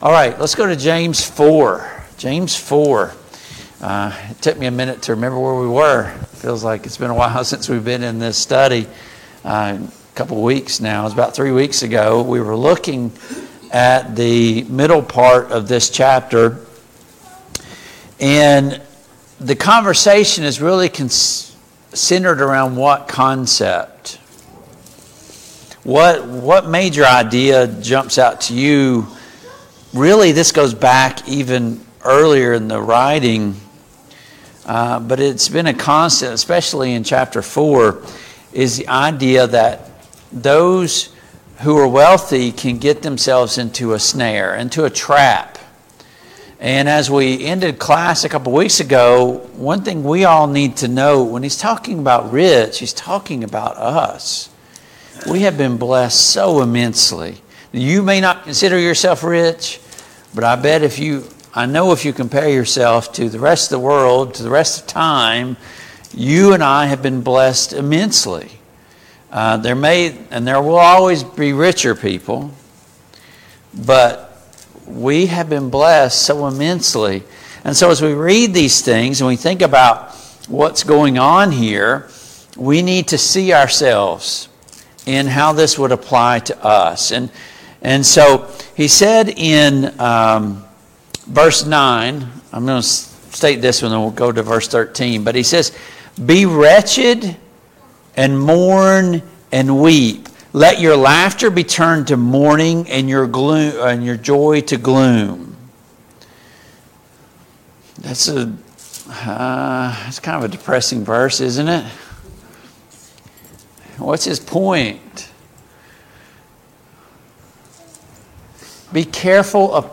Passage: James 4:13-17, James 5:1-6 Service Type: Family Bible Hour